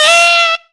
UP SQUEAL.wav